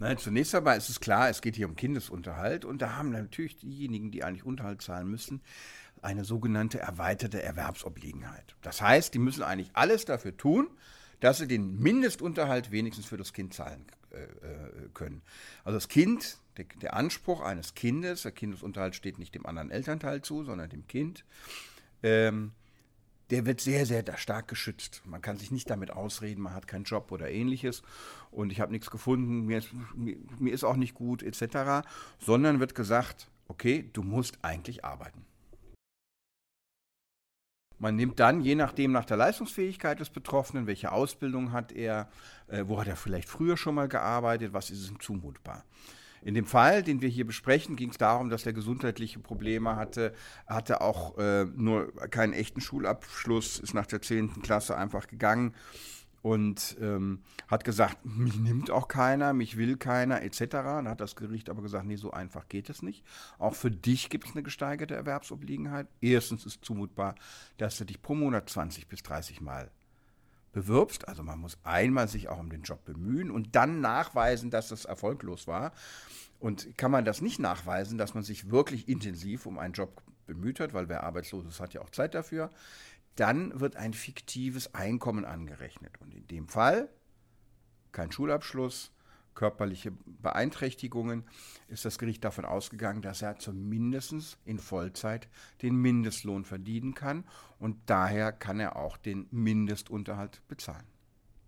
Kollegengespräch: Unterhalt kann nach fiktivem Einkommen berechnet werden